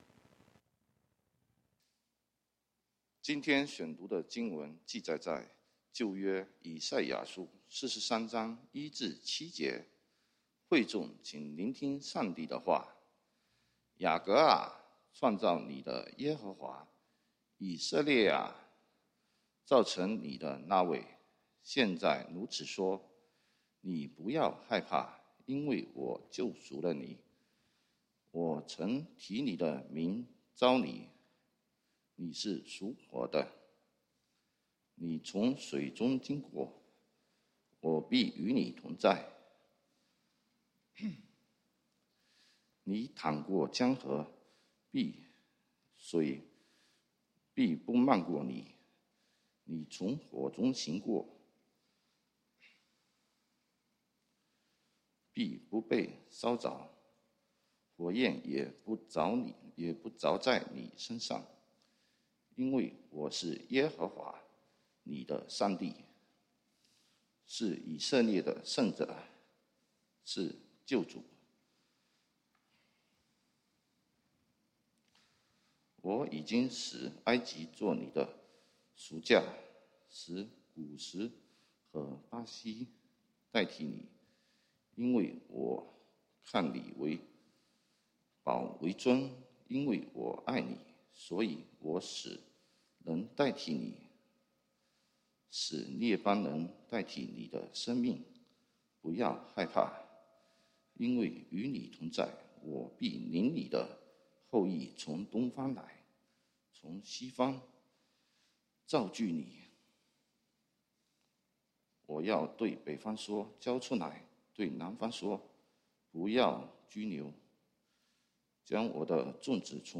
1/9/2022 講道經文：以賽亞書 Isaiah 43:1-7 本週箴言：彼得前書1 Peter 1:6-7 如今，（你們是）在百般的試煉中暫時憂愁，叫你們的信心既被試驗， 就比那被火試驗仍然能壞的金子更顯寶貴，可以在耶穌基督顯現的時候得着 稱讚、榮耀、尊貴。